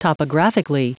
Prononciation du mot topographically en anglais (fichier audio)
Prononciation du mot : topographically